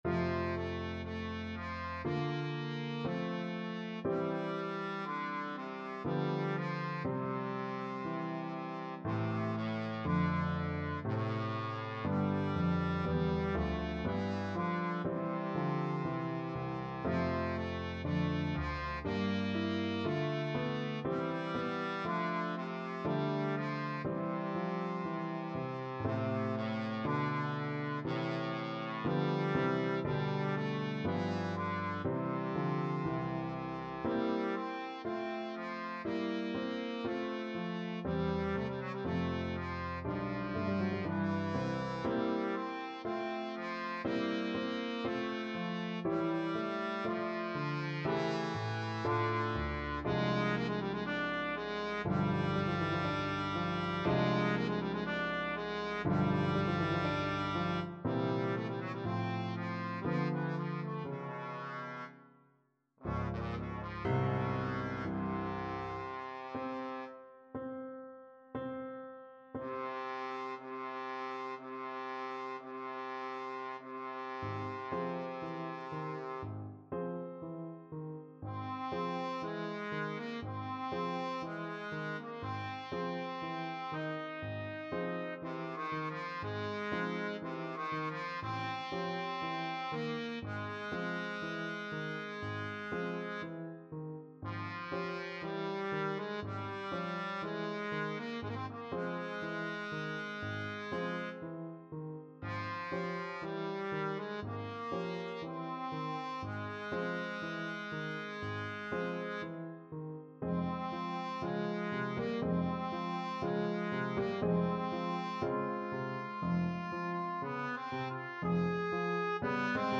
Trombone version
2/4 (View more 2/4 Music)
Andante cantabile =60
Classical (View more Classical Trombone Music)